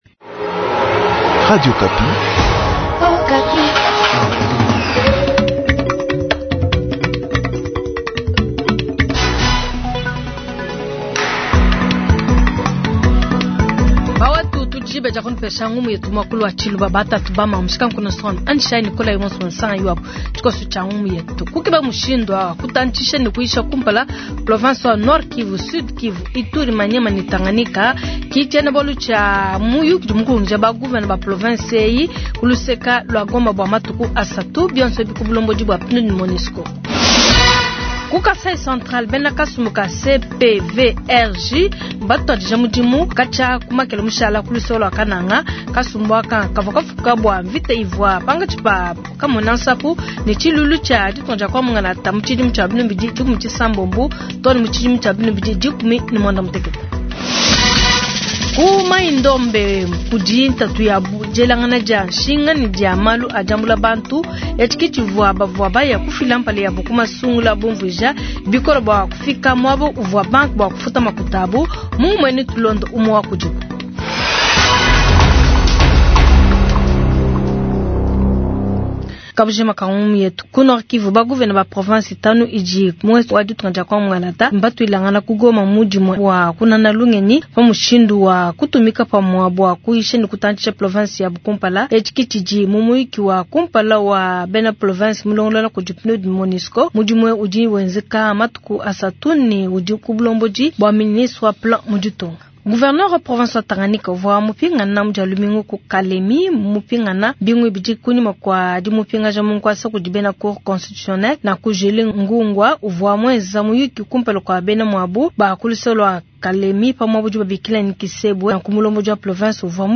Journal Matin